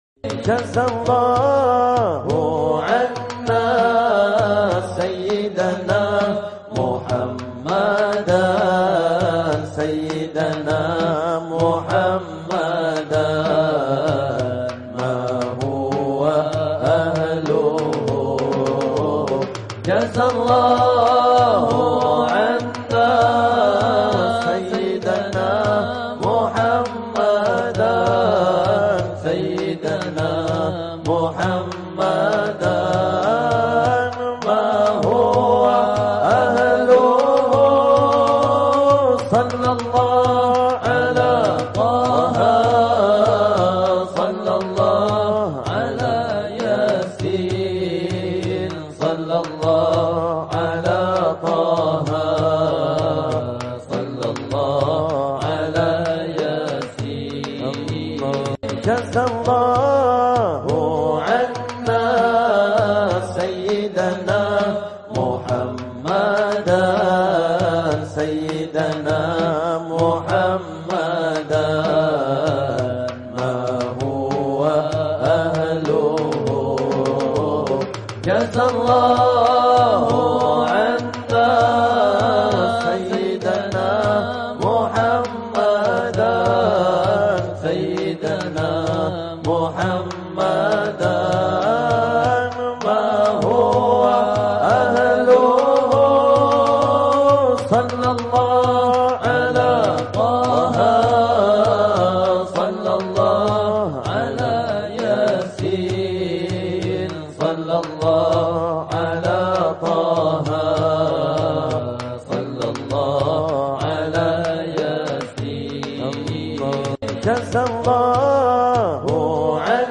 Imam Ath-Thabrani) NASYID SHOLAWAT JAZALLAHU 'ANNA SAYYIDANA MUHAMMADAN MAA HUWA AHLUH - 30 MENIT LINK YOUTUBE SILAHKAN DOWNLOAD MP3 NYA DISINI